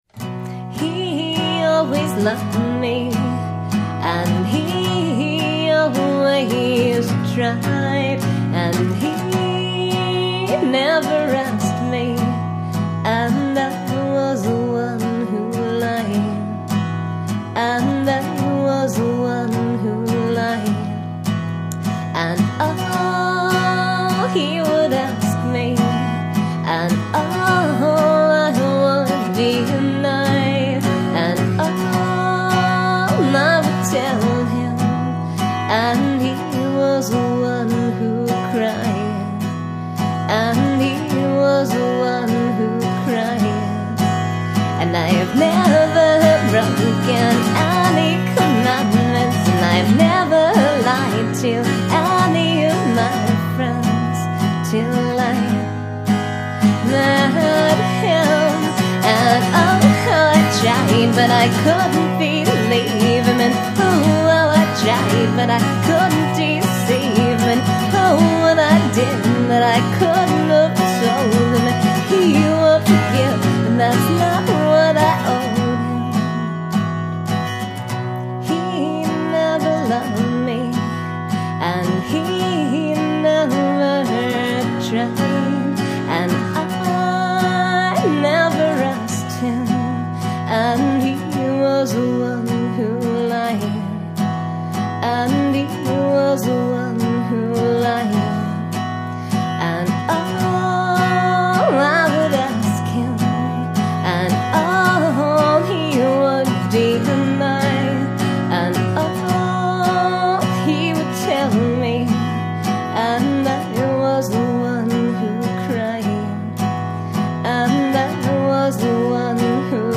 64kbps mono MP3
guitar and vocals